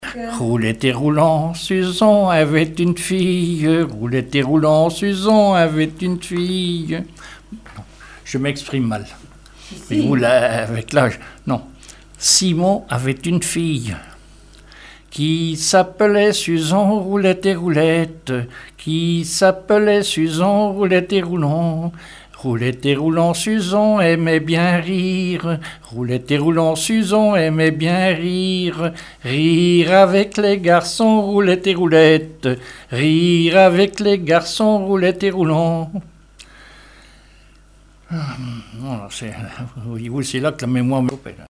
Genre laisse
Témoignages et chansons
Pièce musicale inédite